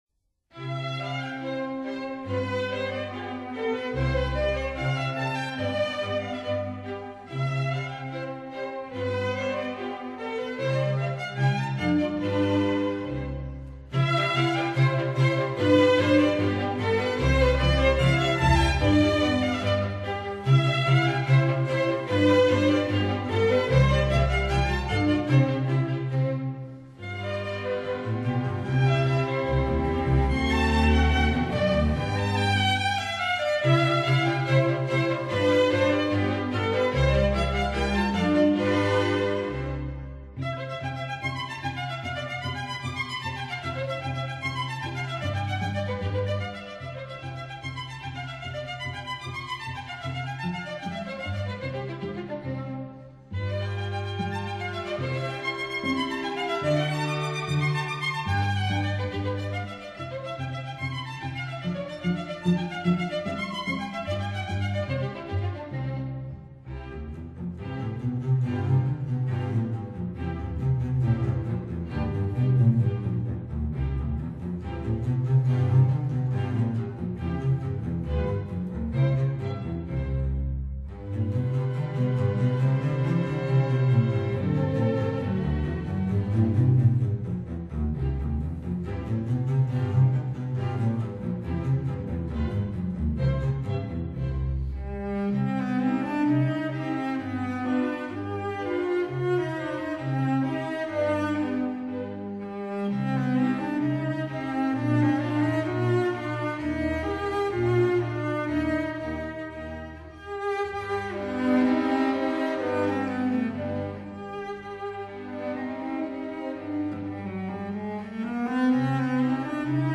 大提琴
低音提琴